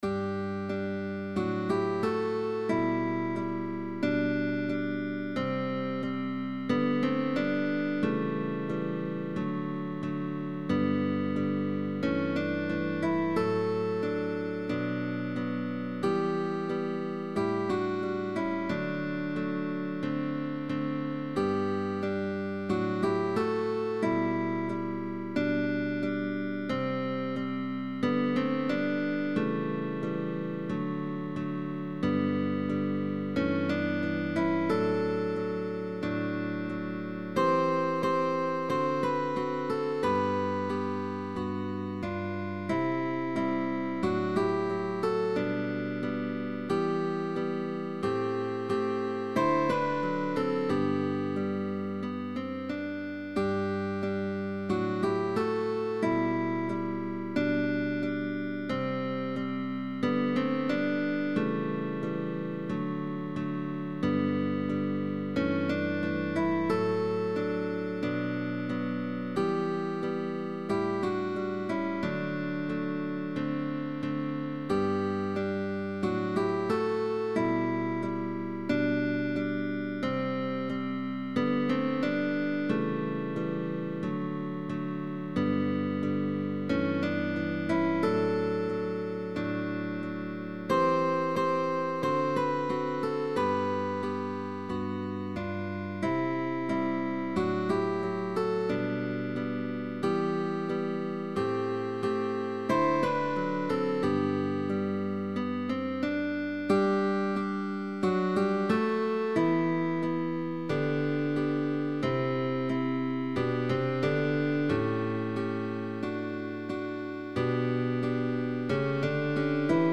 GUITAR QUARTET 4 guitar quartets